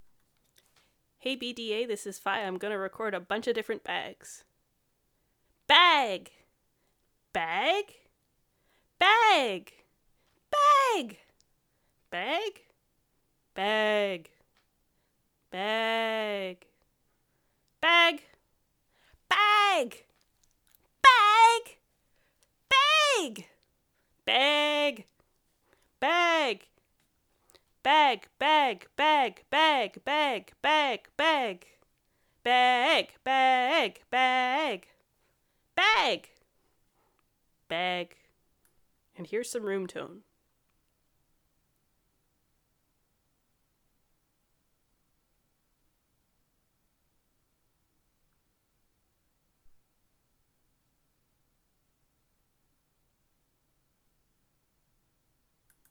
It's super super simple, all I need you to do is record yourself saying the word "Bag!" in a really upbeat and studio audience-y sort of way.